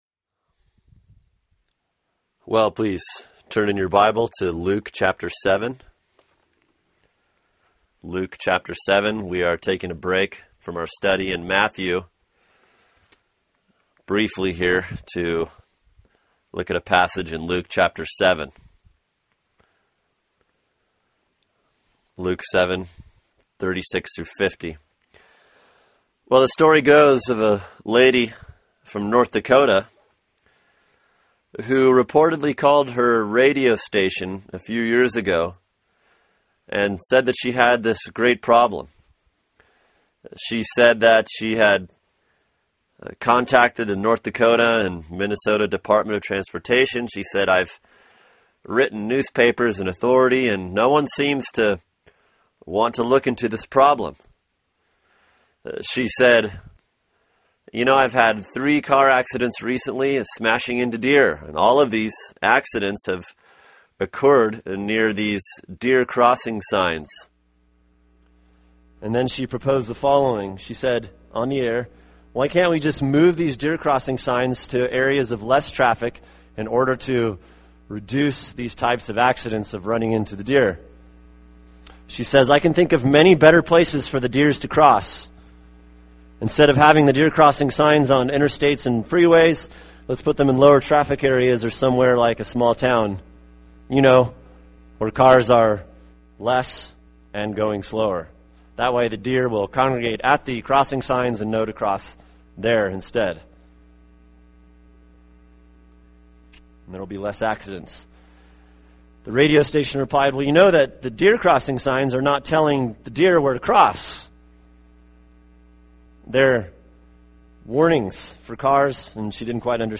[sermon] Luke 7:36-50 “Peace” | Cornerstone Church - Jackson Hole